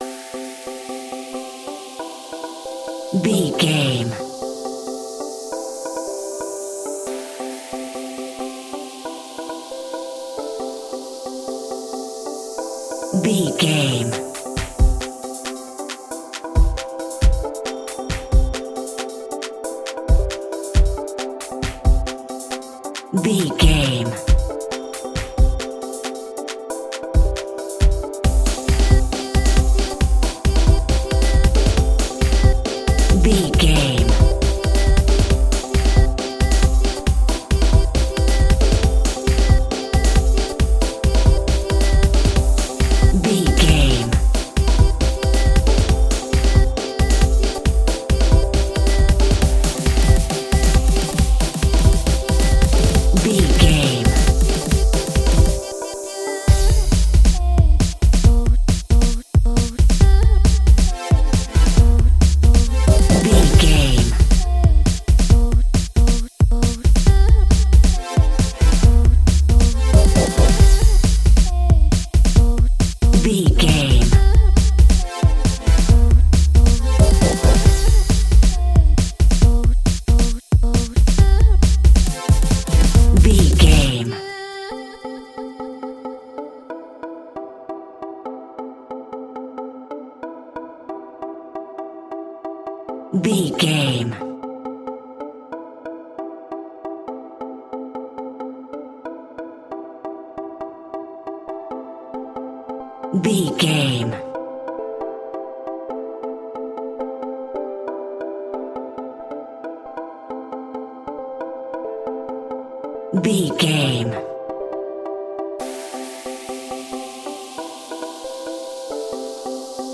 Epic / Action
Fast paced
Mixolydian
aggressive
powerful
dark
funky
groovy
futuristic
driving
energetic
drum machine
synthesiser
breakbeat
synth leads
synth bass